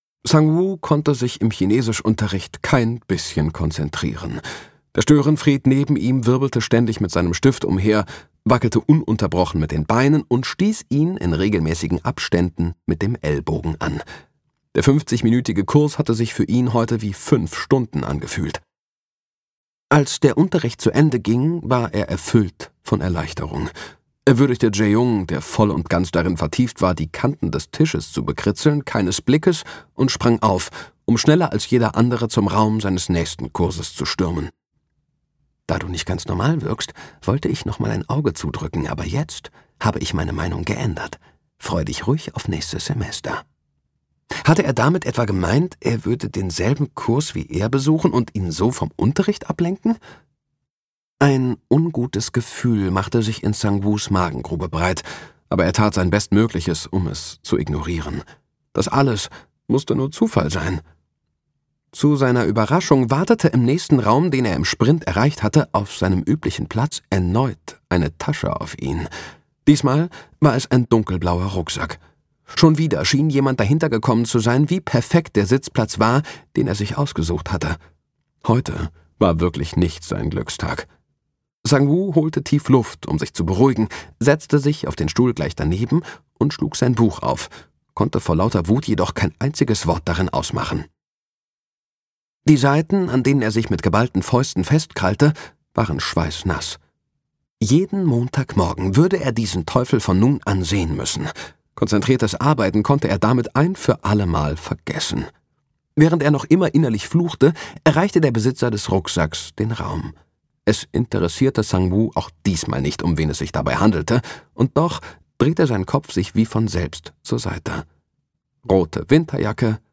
Seine warme, angenehme ...